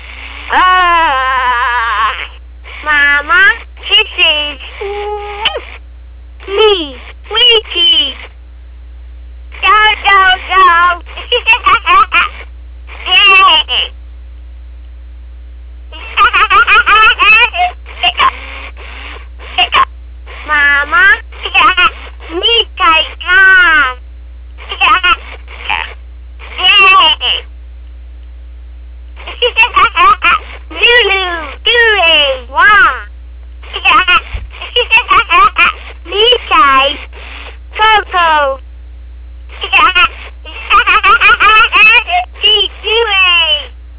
I've put together some sounds of my Furby Babies so you can hear the differances between adult Furby and Baby Furby.